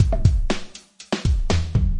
Tag: 回路 节奏 命中 岩石 撞击 120-BPM 打击乐器 节拍 敲击循环 量化 鼓循环 常规